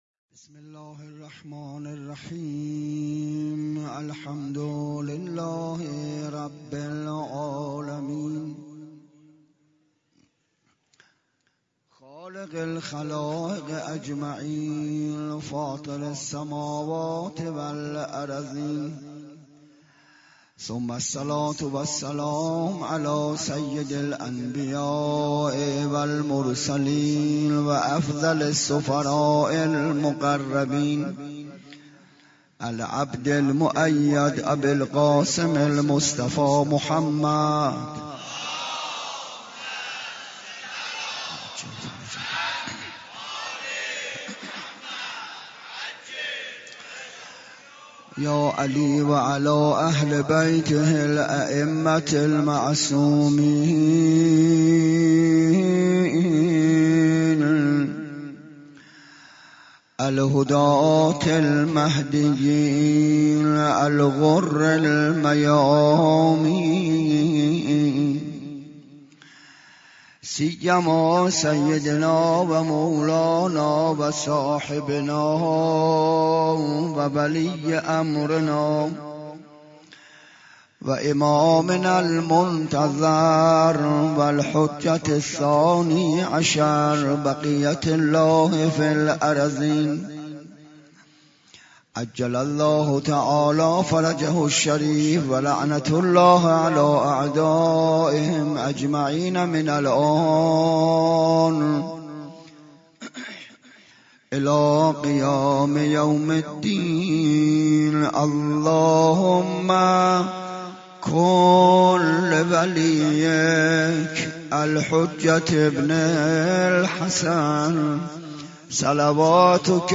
13 محرم 97 - حسینیه کربلایی های یزد - ریشه یابی واقعه عاشورا